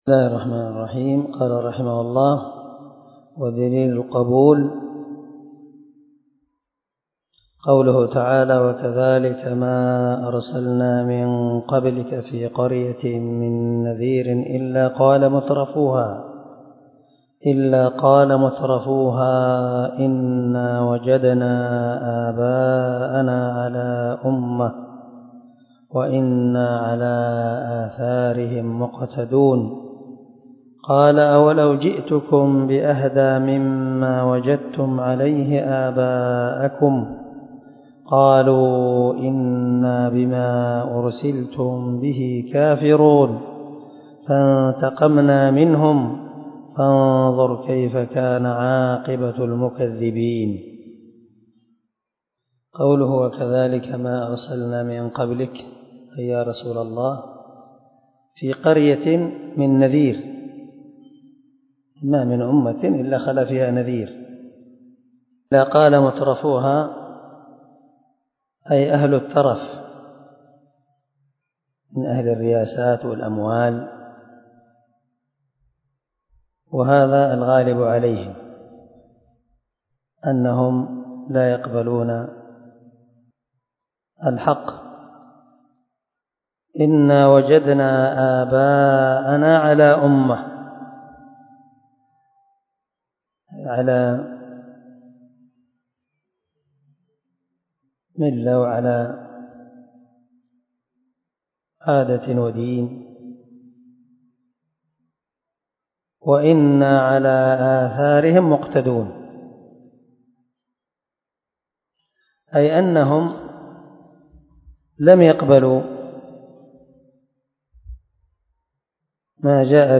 🔊الدرس 10 أدلةشرط القبول ( من شرح الواجبات المتحتمات)